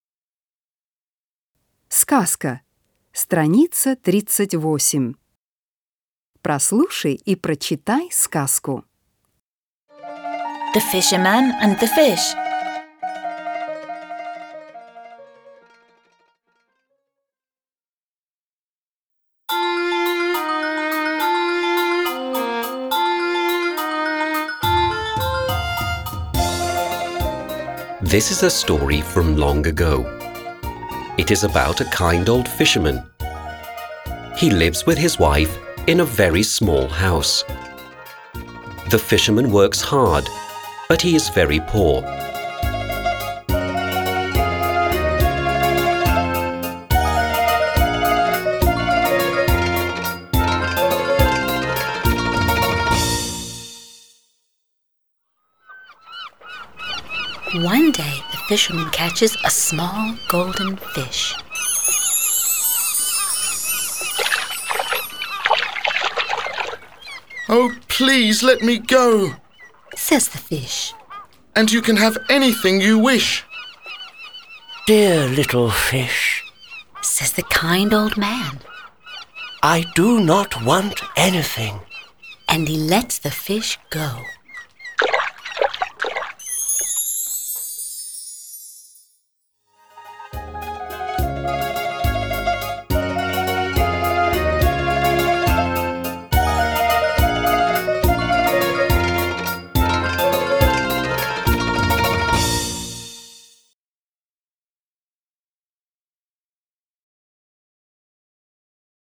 09-Сказка-с.-38.mp3